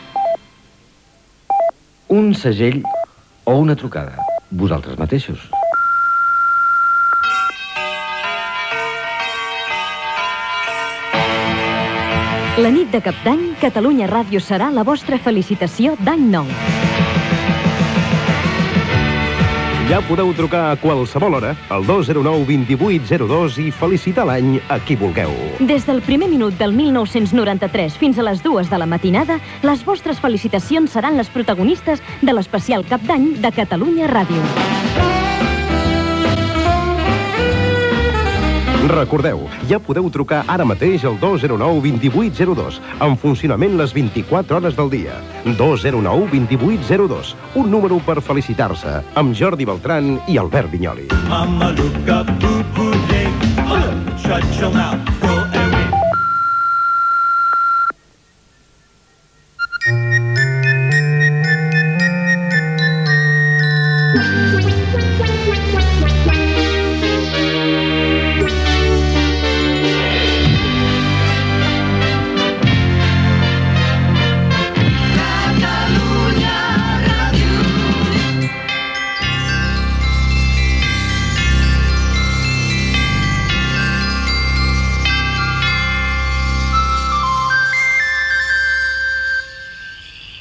Promoció de "L'especial de cap d'any de Catalunya Ràdio", amb el telèfon de participació, Indicatiu nadalenc de l'emissora
FM